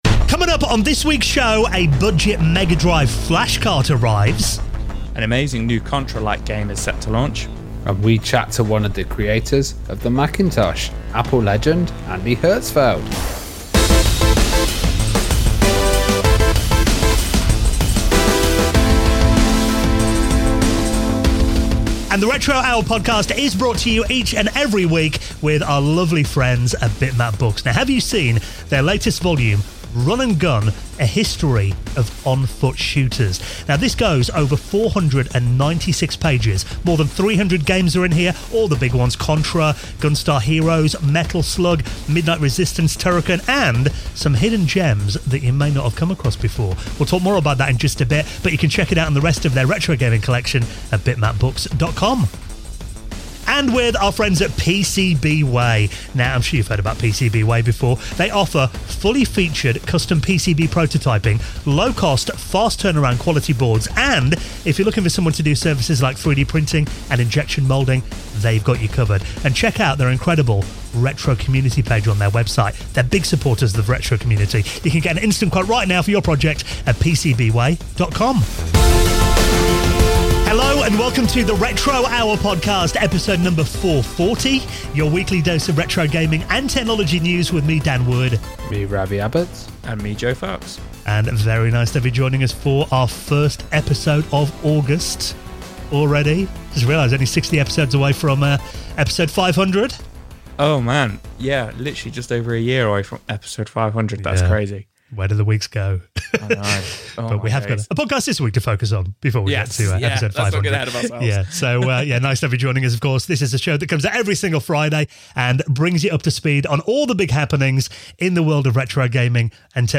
This week, we're joined by Andy Hertzfeld, a key figure behind the creation of the original Apple Macintosh
Andy Hertzfeld Interview